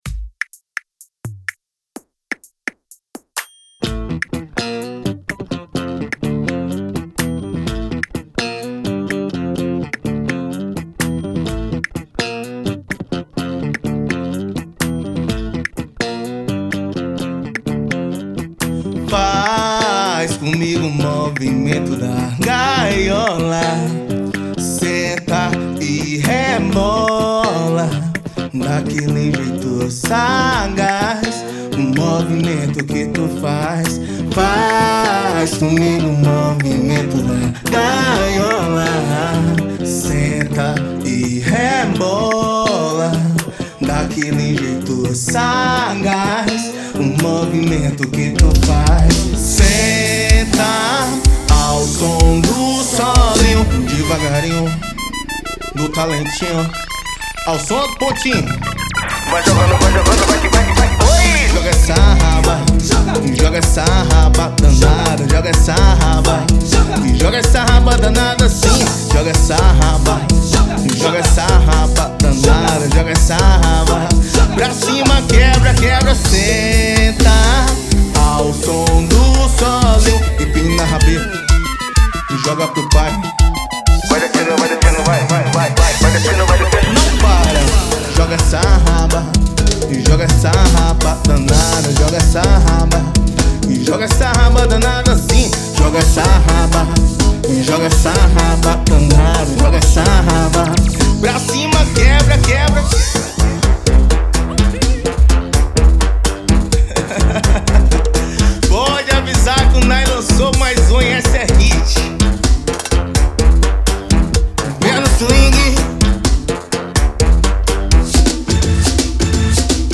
EstiloBregadeira